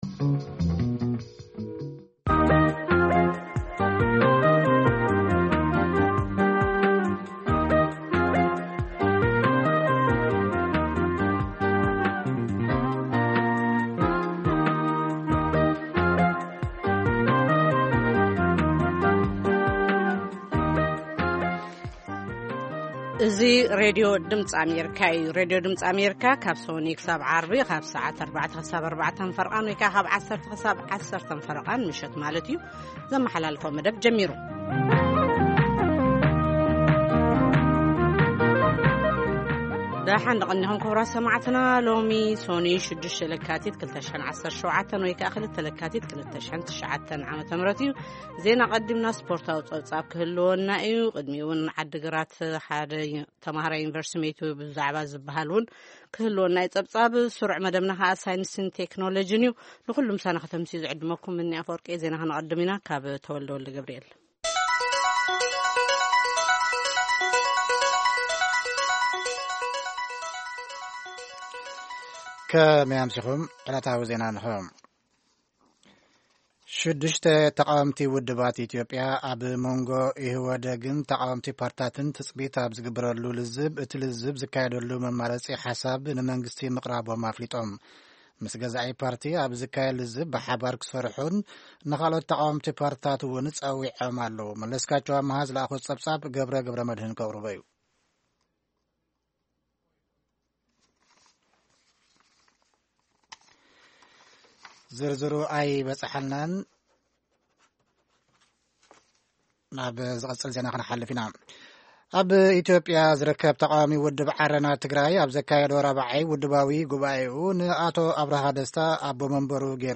ፈነወ ትግርኛ ብናይ`ዚ መዓልቲ ዓበይቲ ዜና ይጅምር ። ካብ ኤርትራን ኢትዮጵያን ዝረኽቦም ቃለ-መጠይቓትን ሰሙናዊ መደባትን ድማ የስዕብ ። ሰሙናዊ መደባት ሰኑይ፡ ሳይንስን ተክኖሎጂን / ሕርሻ